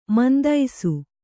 ♪ mandaisu